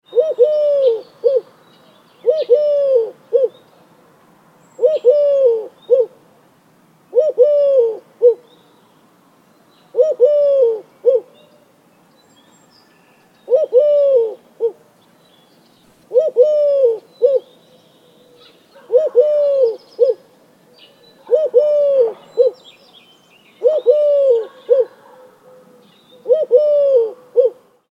Eurasian Collared Dove Call – Authentic Bird Nature Sound Effect
Description: Eurasian Collared Dove call – authentic bird nature sound effect. This spring nature sound effect features the clear, rhythmic call of the Eurasian Collared Dove.
Clean HD audio recording delivering authentic outdoor ambience and the dove’s distinctive three-note song.
Eurasian-collared-dove-call-authentic-bird-nature-sound-effect.mp3